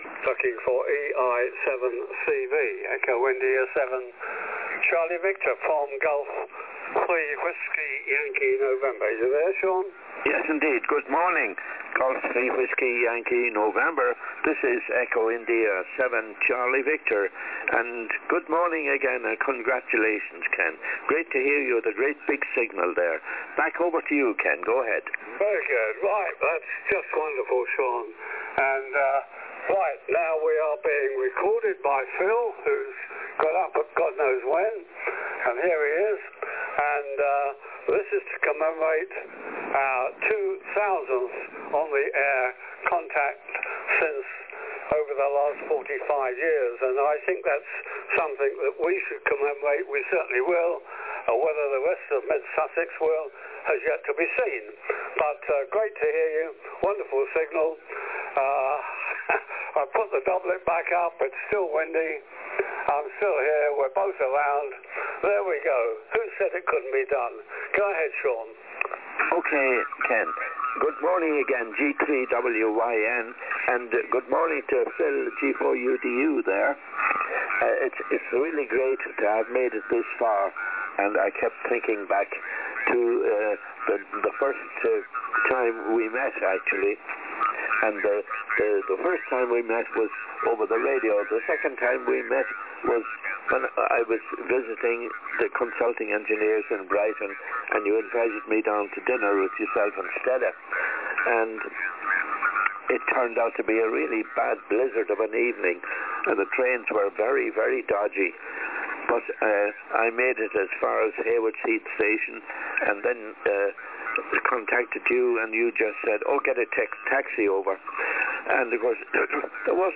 Thus began a regular series of Sunday morning contacts on 80 metres at 08:00 hrs local time.